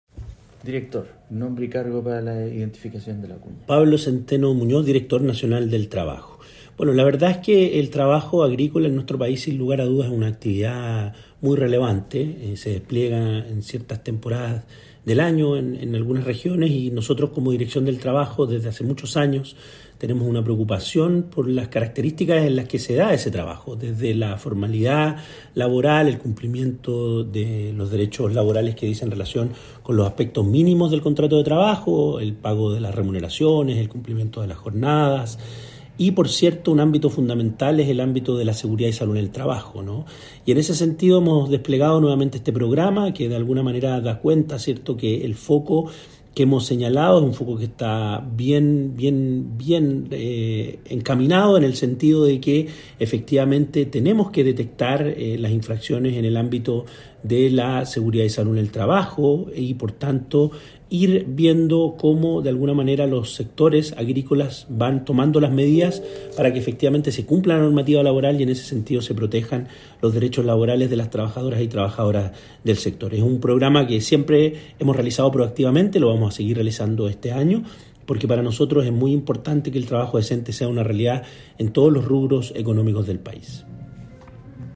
El director del Trabajo, Pablo Zenteno Muñoz, explicó que